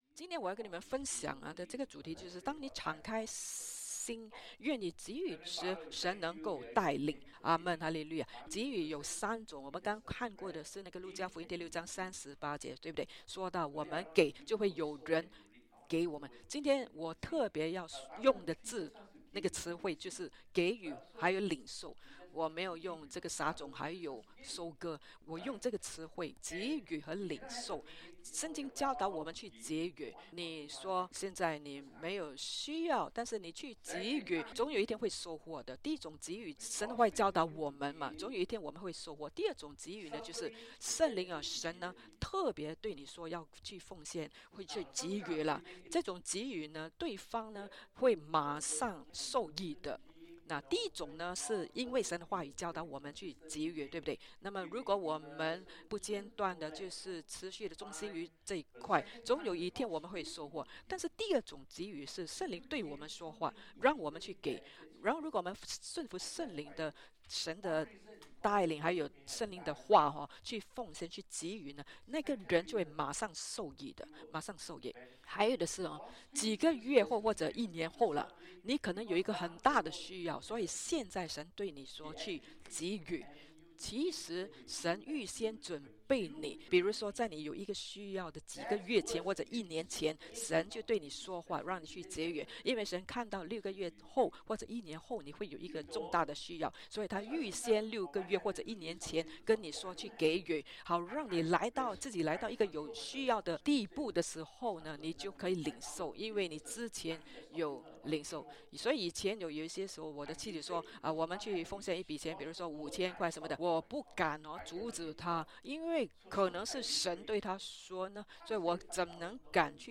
Sermons – Page 9 – Faith Church Singapore